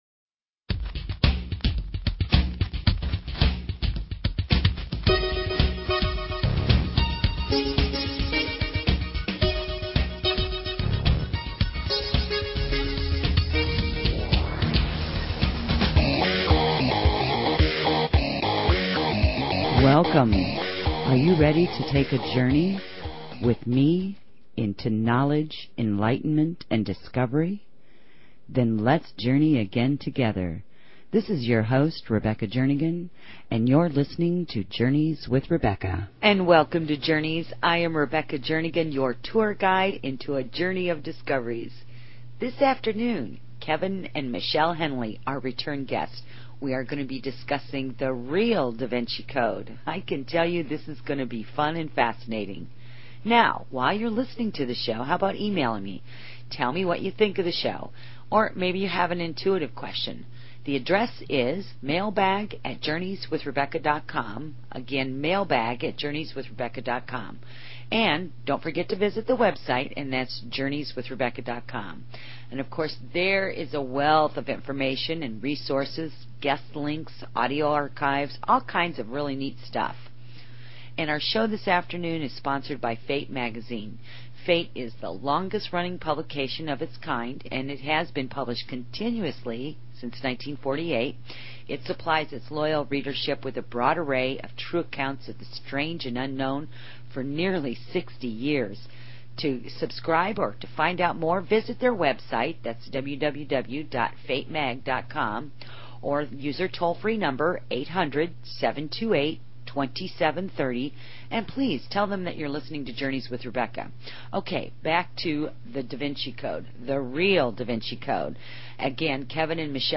Talk Show Episode
Interview